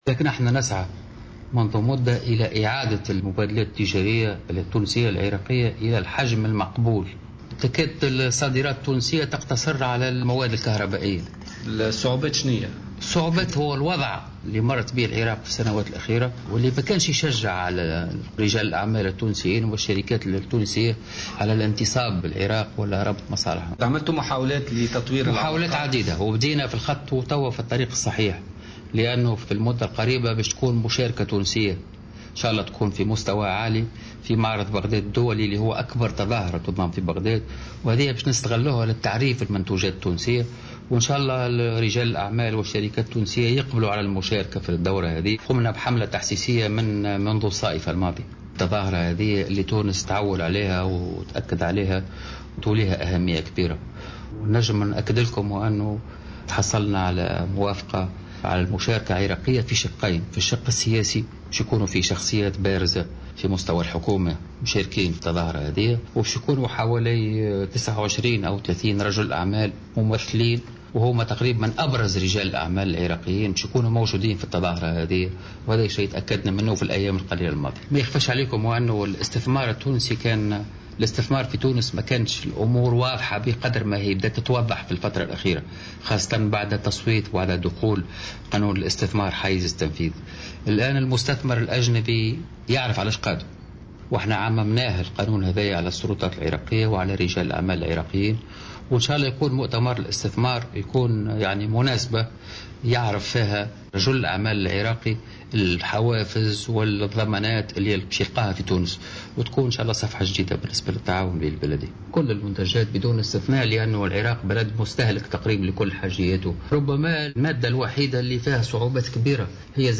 وأشار السفير في تصريح لموفد "الجوهرة أف أم" للعراق إلى وجود مساعي حثيثة من أجل تدعيم التعاون الاقتصادي والتجاري، مضيفا أنه تم تأكيد المشاركة التونسية في معرض بغداد الدولي بهدف التعريف بالمنتوجات التونسية، إضافة إلى مشاركة حوالي 30 رجل أعمال عراقي في ملتقى الاستثمار الذي سيقام قريبا في تونس.